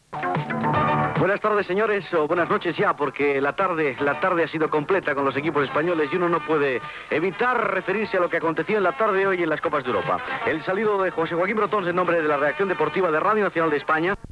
Salutació a l'audiència del programa, amb una errada en la formulació de la frase
Esportiu